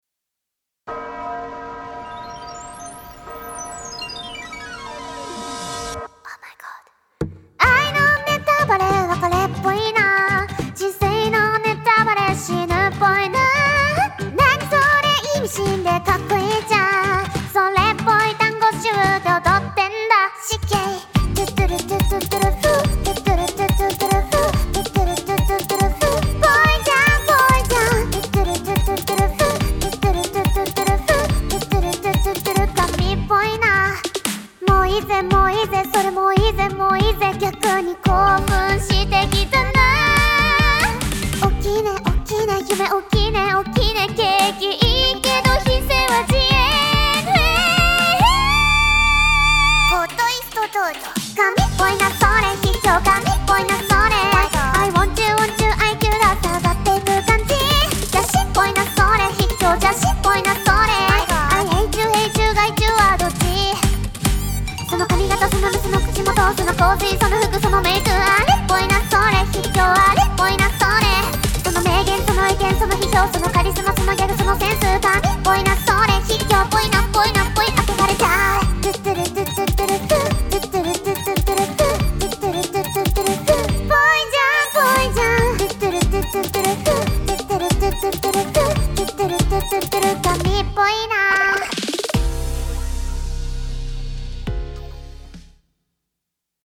Sample Mix (Voacl Mix)